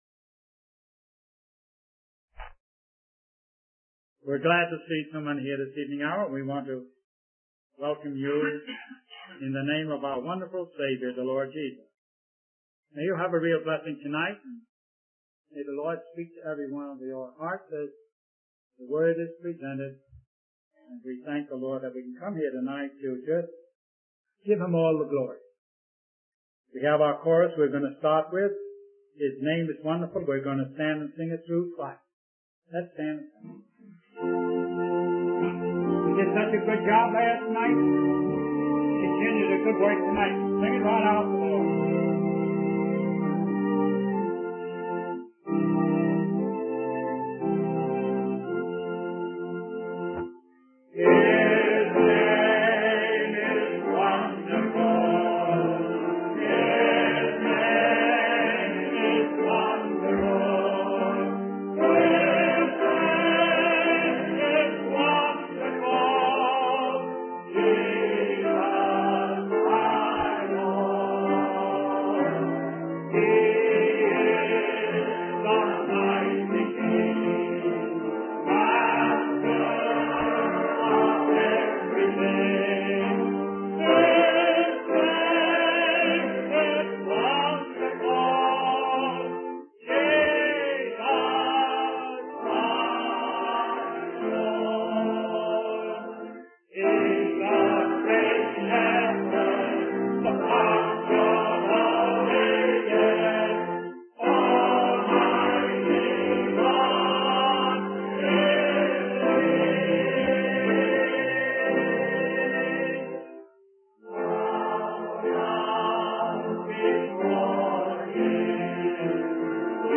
In this sermon, the preacher discusses the story of Cain and Abel from the book of Genesis. He emphasizes the importance of giving to God and the consequences of not doing so.